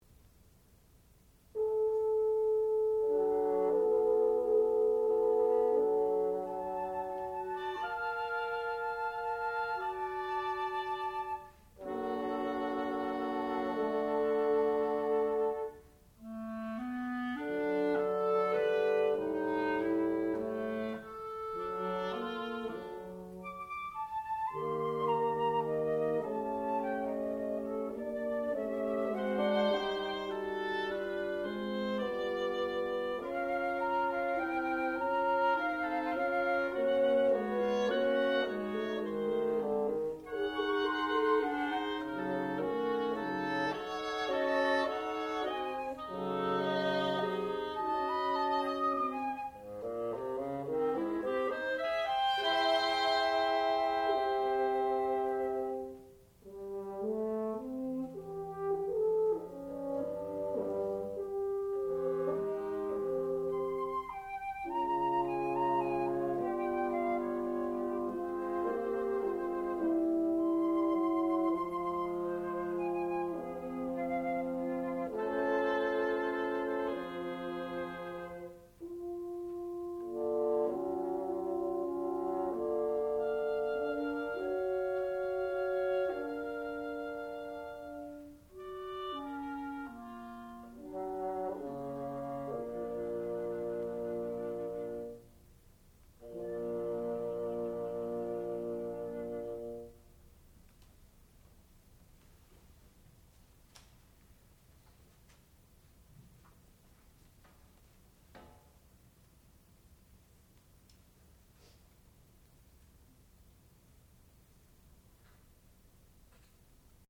sound recording-musical
classical music
bassoon
horn
flute